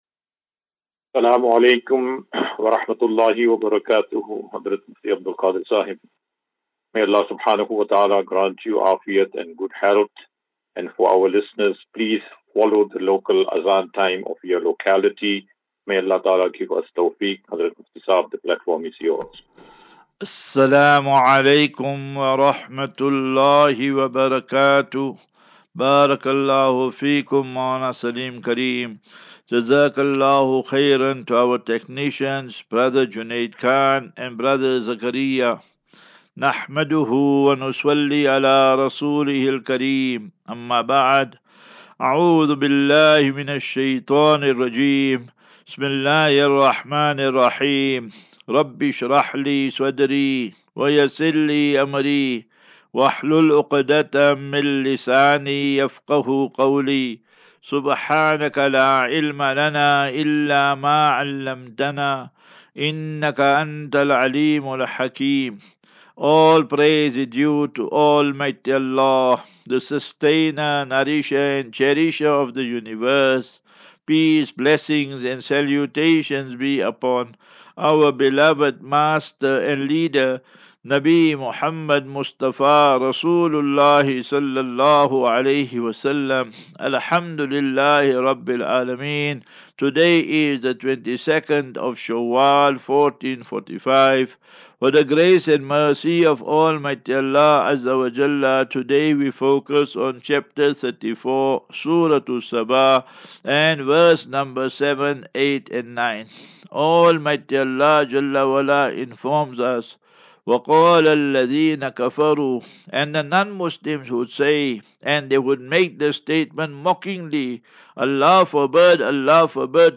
Assafinatu - Illal - Jannah. QnA